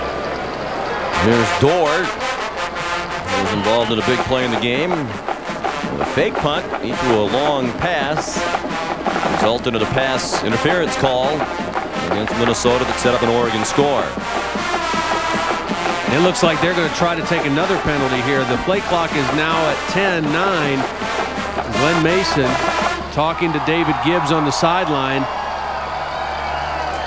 The Trombone section has several "section cheers" that we perform during games.
Rubber Ducky (for the Oregon Ducks at the Sun Bowl) (.wav file)